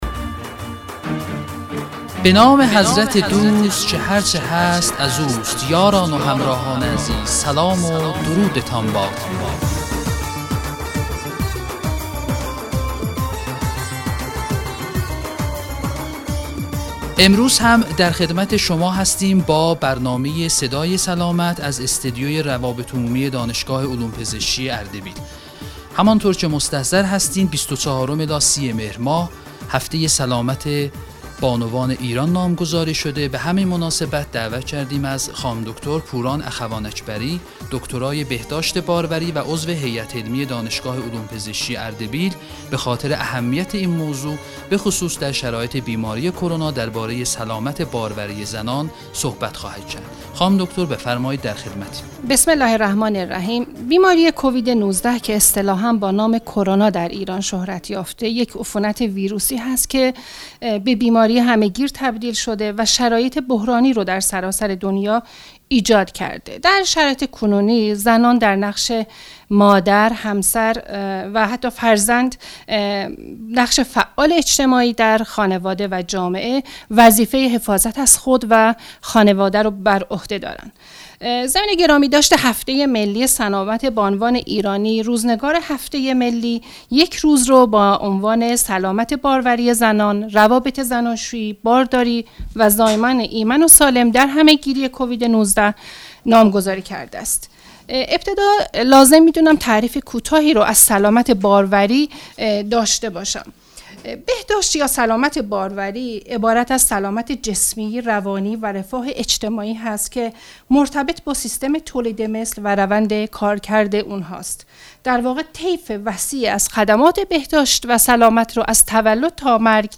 برنامه رادیویی صدای سلامت میهمان این برنامه